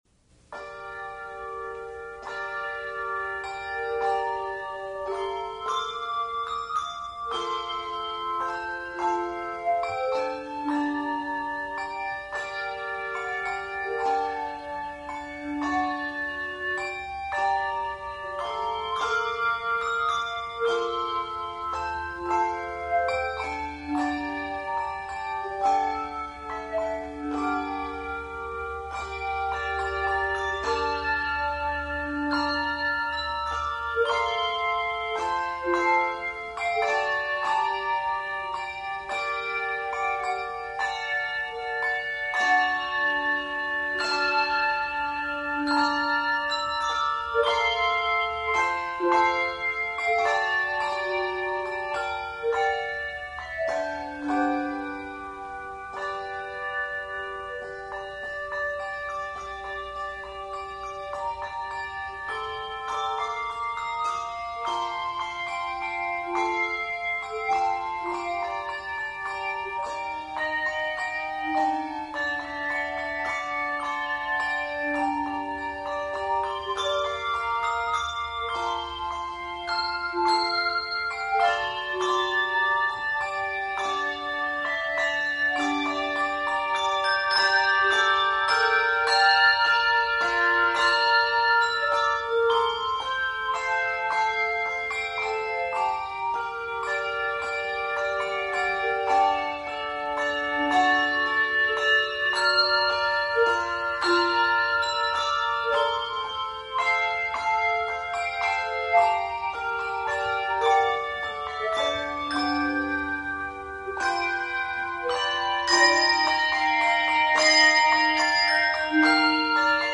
Review: A sensitive setting of a Christmas favorite.